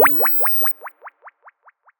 Index of /musicradar/sci-fi-samples/Theremin
Theremin_FX_12.wav